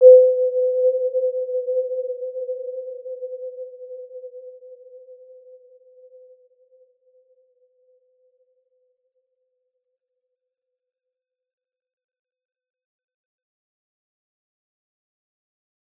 Warm-Bounce-C5-p.wav